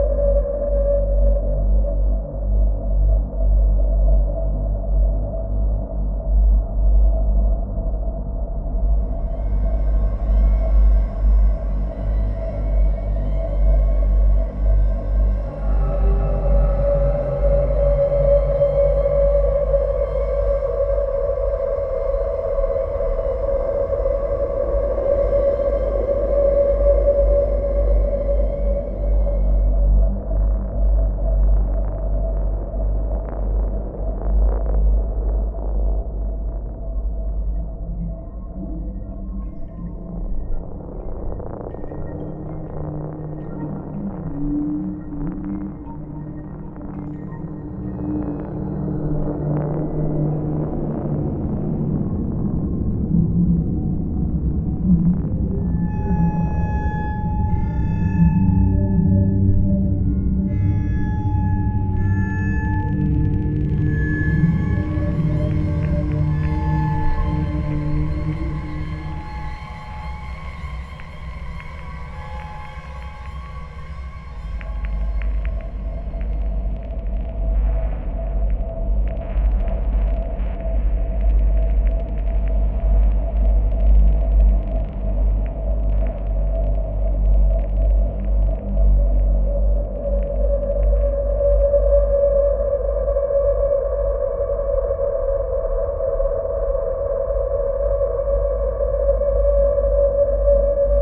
cool sounding ambient track